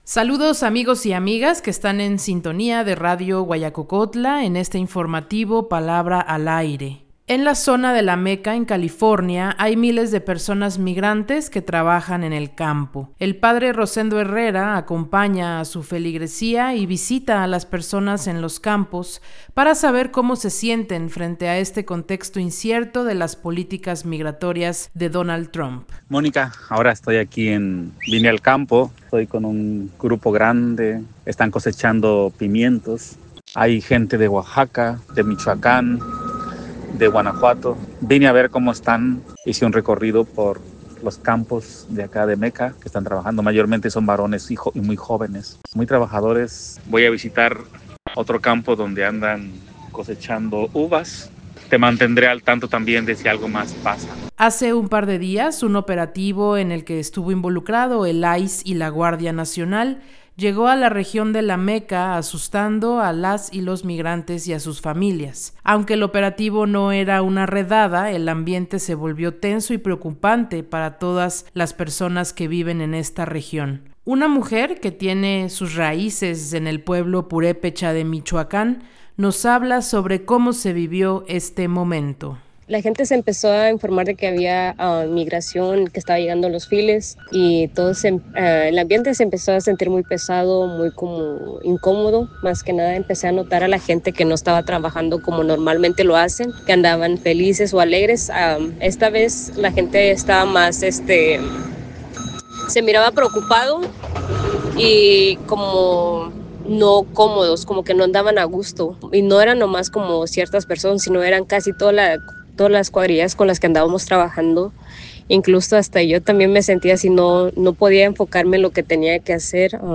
En los campos agrícolas de California se vive mucha incertidumbre sobre las deportaciones y el clima hostil hacia las personas migrantes. El reporte